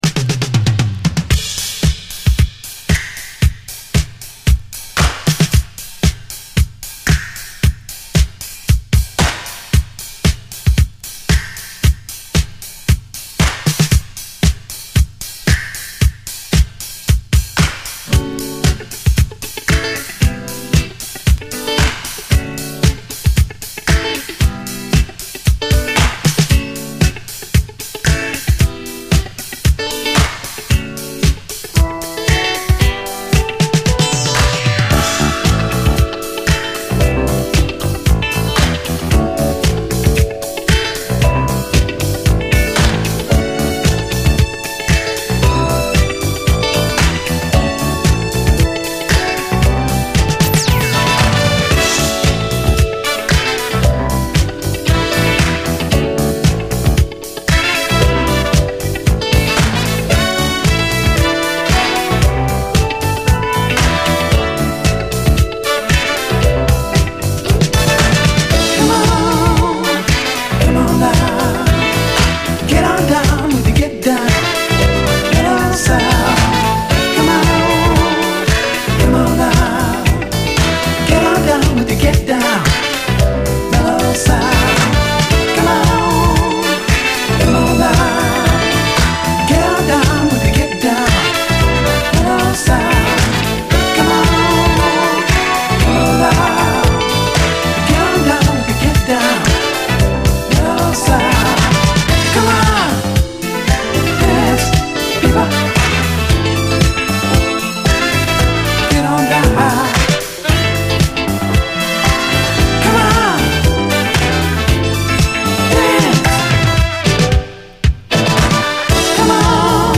SOUL, 70's～ SOUL, DISCO
非常に今風な最高トロピカル・メロウ・ブギーのダブル・サイダー！
イントロのブレイクに爽やかなギター・カッティングが流れ込んで来る時の快感！
ハンドクラップの残響までも気持ちよく、サマー・フィール感じます！